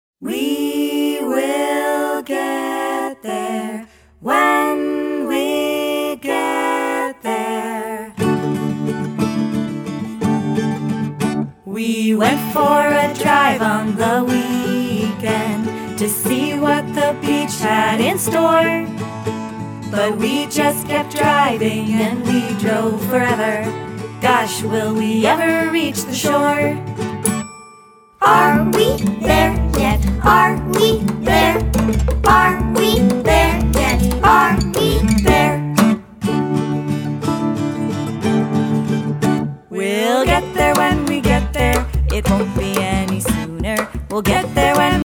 All tracks except Radio Edits include scripted dialogue.